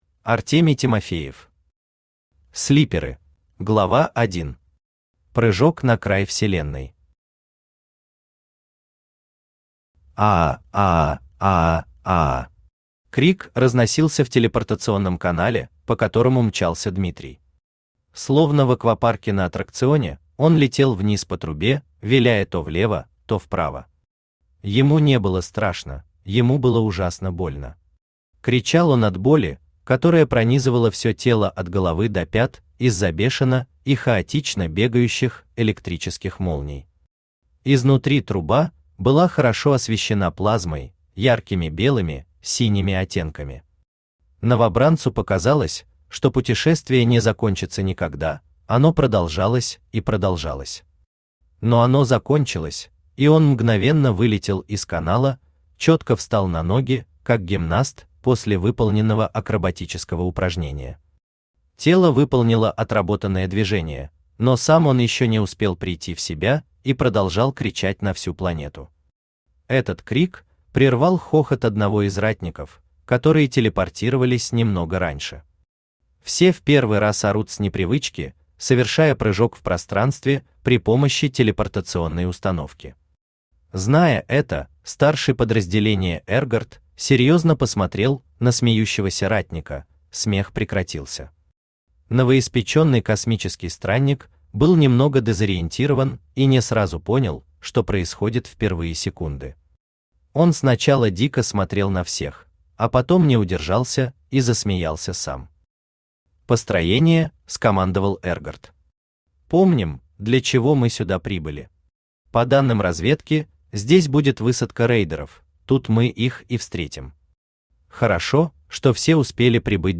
Аудиокнига Слиперы | Библиотека аудиокниг
Aудиокнига Слиперы Автор Артемий Тимофеев Читает аудиокнигу Авточтец ЛитРес.